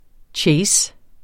Udtale [ ˈtjεjs ]